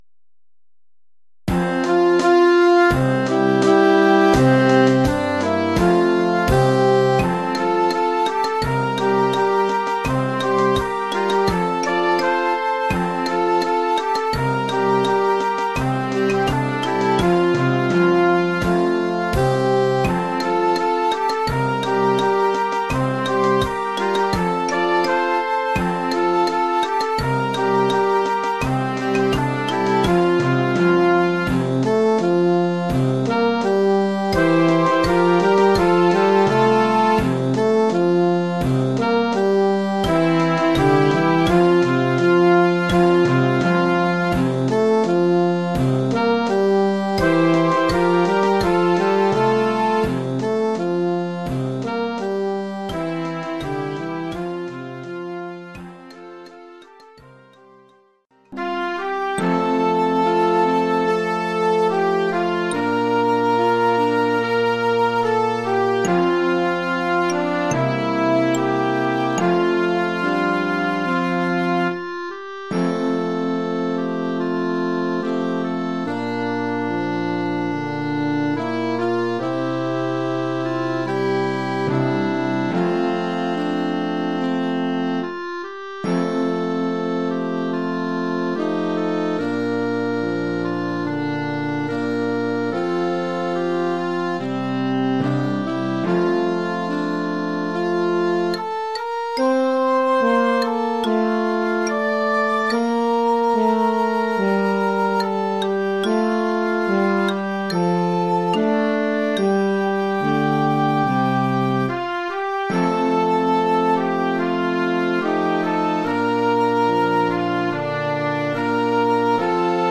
Collection : Harmonie (Classe d'orchestre)
Oeuvre en quatre mouvements pour
harmonie junior (ou classe d’orchestre).